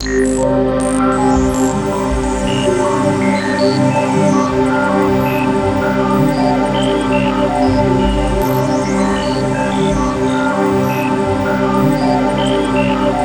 SEQ PAD03.-R.wav